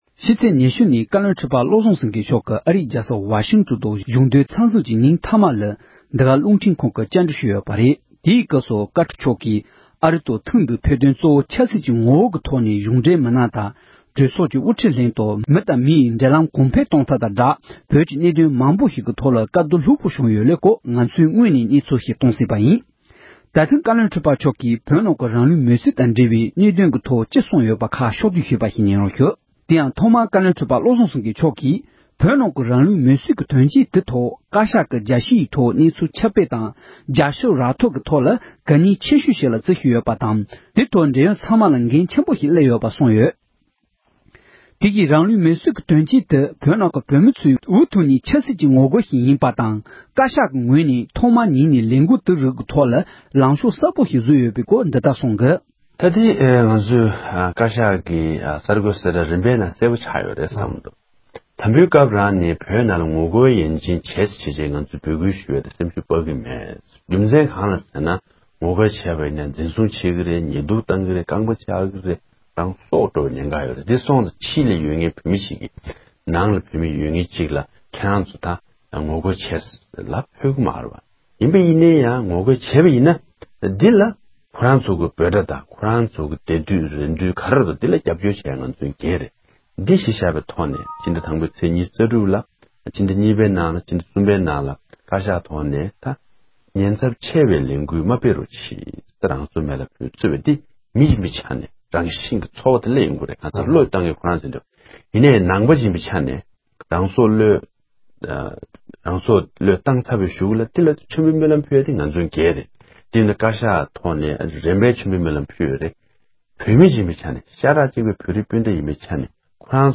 རང་ལུས་མེ་སྲེག་འབྱུང་དང་མི་འབྱུང་རྒྱ་ནག་གི་ལག་ཏུ་ཡོད། བཀའ་བློན་ཁྲི་པ་མཆོག་ནས་ཨེ་ཤེ་ཡ་རང་དབང་རླུང་འཕྲིན་ཁང་དུ་བཀའ་མོལ་གནང་བཞིན་པ།
སྒྲ་ལྡན་གསར་འགྱུར། སྒྲ་ཕབ་ལེན།